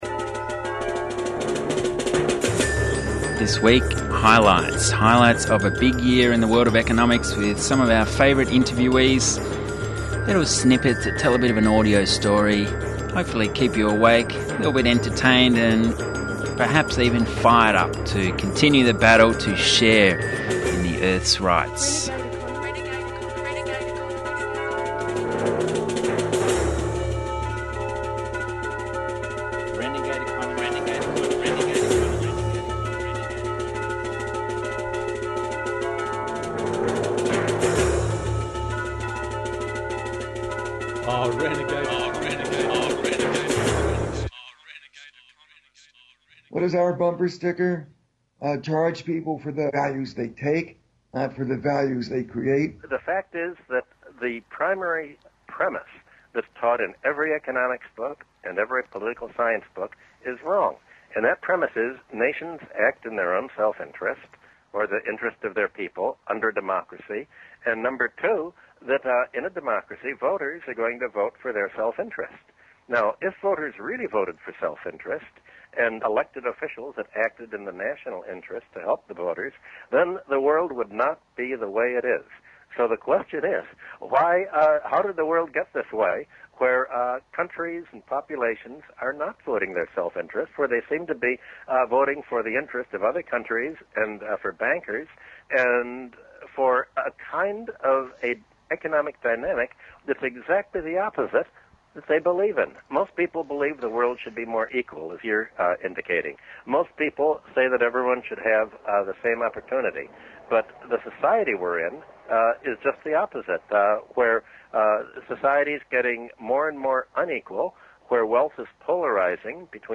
A year of highlights with audio snippets telling the year that it was.